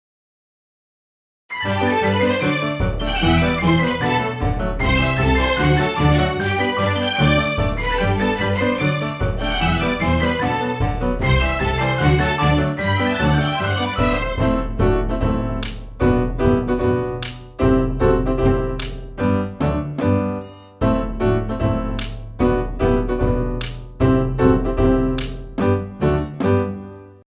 Next thing is trying to replace the piano parts in mock orchestra. I’m pretty horrible but here is the preliminary sketch.